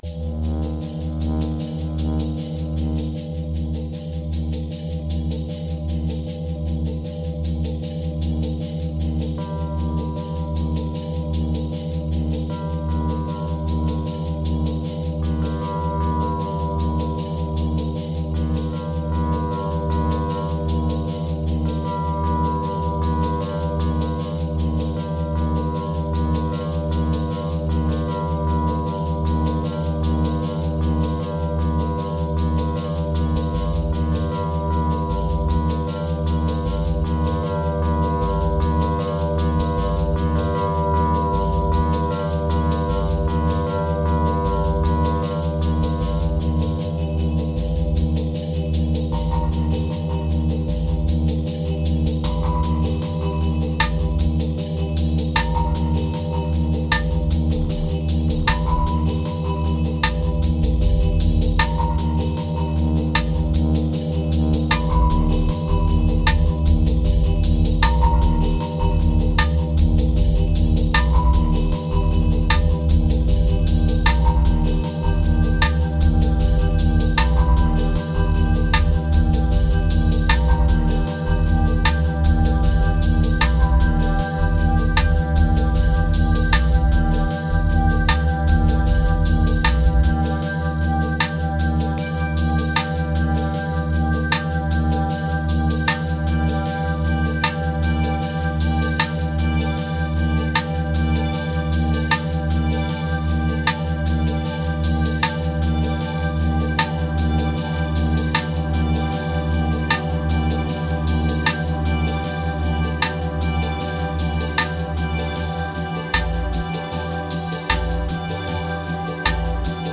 Memories Relief Moods Rim Rddim Signal Ladder Epic